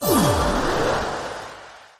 Symbol_Change_Sound.mp3